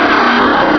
sovereignx/sound/direct_sound_samples/cries/marshtomp.aif at master